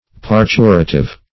Parturitive \Par*tu"ri*tive\, a. Pertaining to parturition; obstetric.